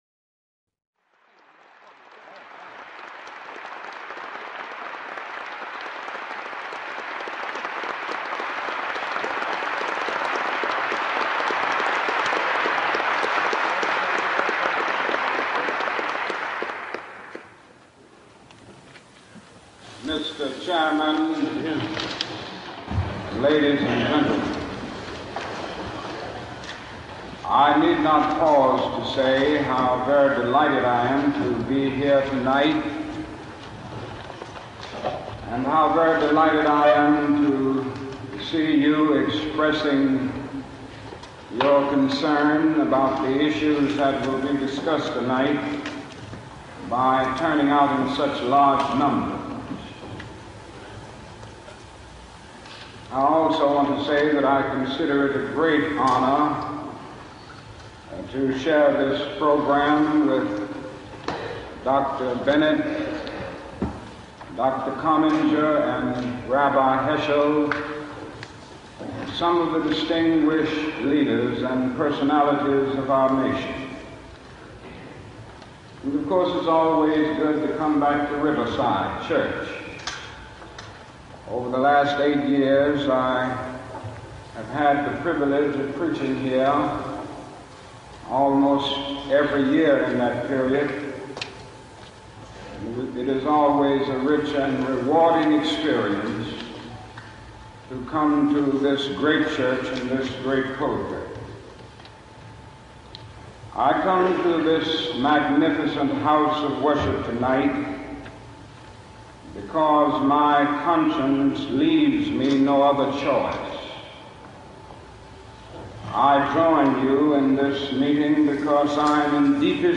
Beyond Vietnam - Martin Luther King, Riverside Church, NYC, 4 Apr 1967
Listen to a recording of the complete speech, 56:48 mins.